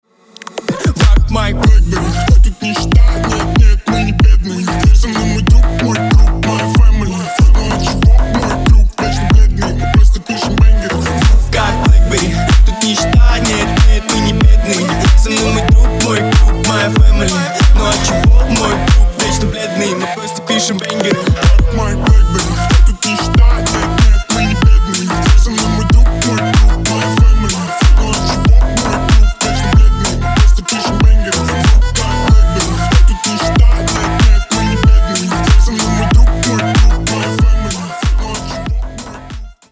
Ремикс # Рэп и Хип Хоп
клубные # громкие